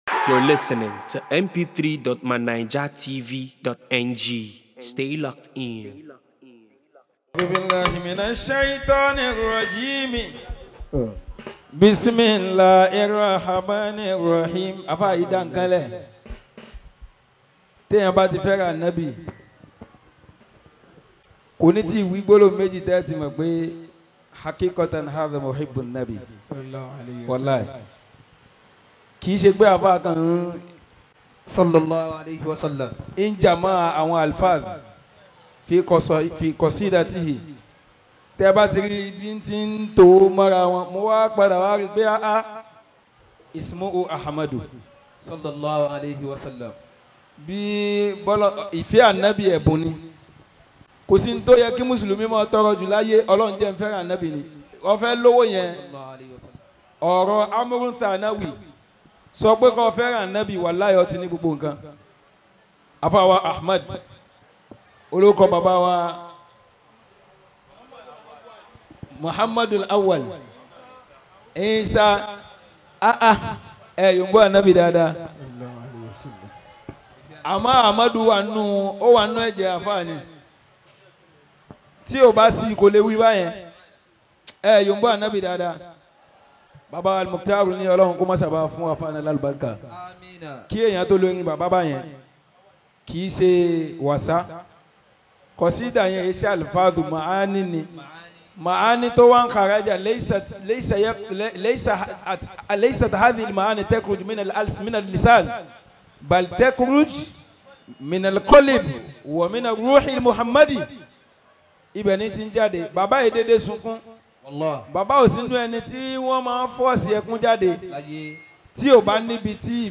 at Modrasat Ridwanullahi Institute of Arabic & Islamic Studies Annual Mealad Rosul on the 6th of September 2025.